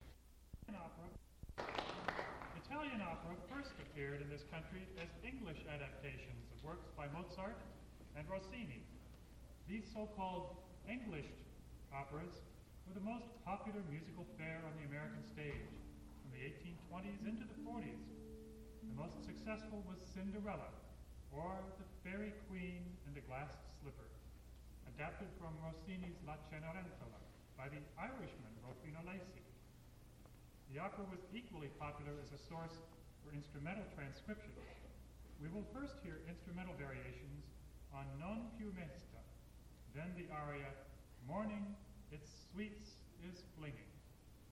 Spoken intro for Variations on "Non piu mesta" from La Cenerentola and "Morning Its Sweets is Flinging" from Cinderella